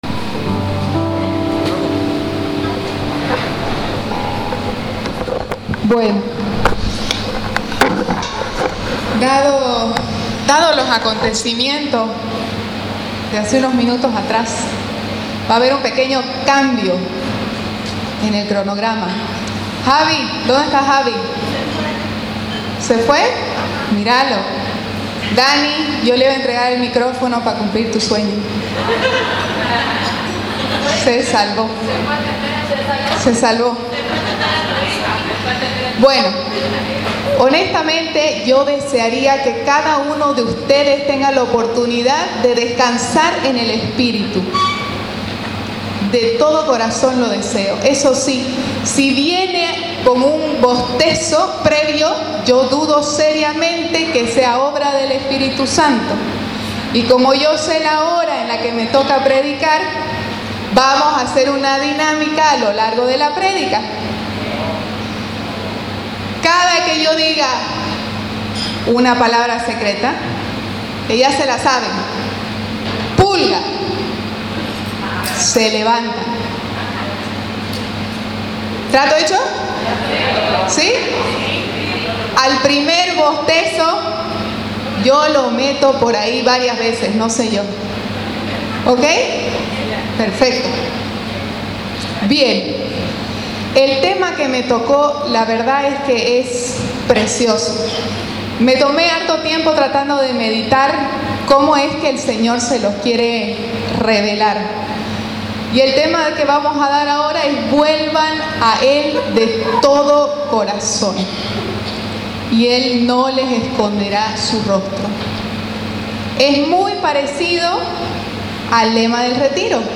Lugar: Hotel Novotel